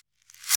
Closed Hats
07_Perc_17_SP.wav